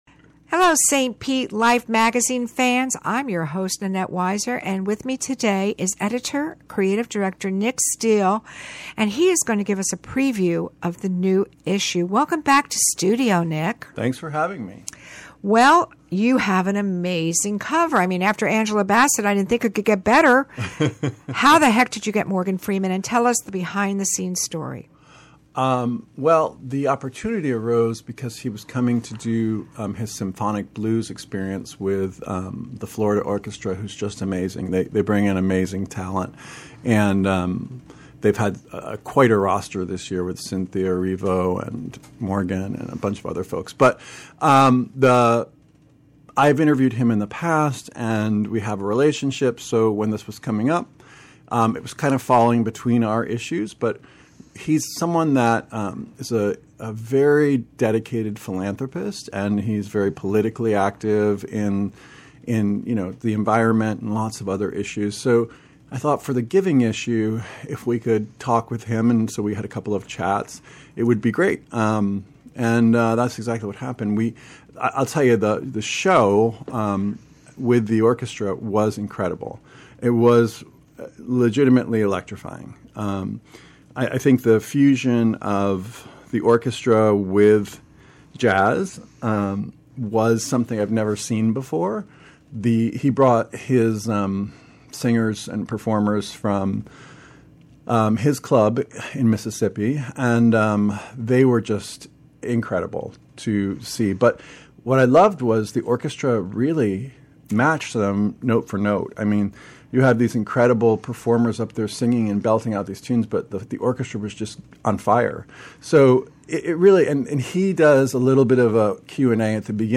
Lunchtime Conversation